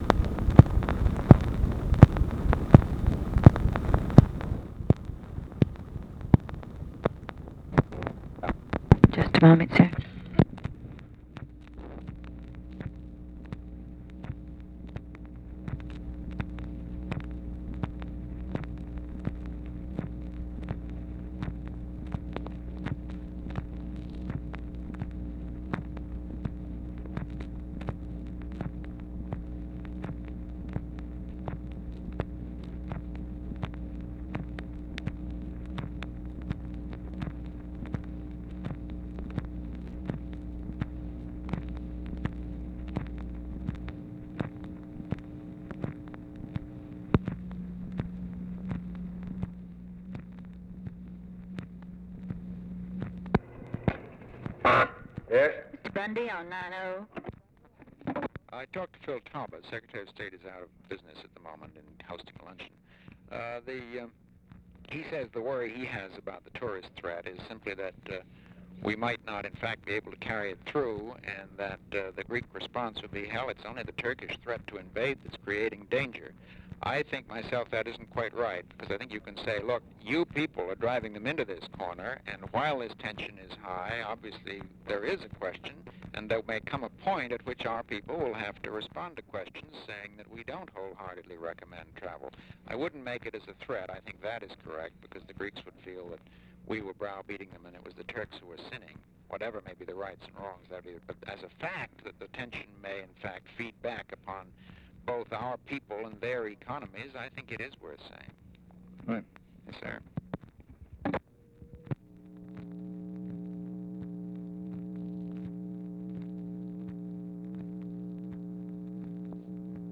Conversation with MCGEORGE BUNDY, June 11, 1964
Secret White House Tapes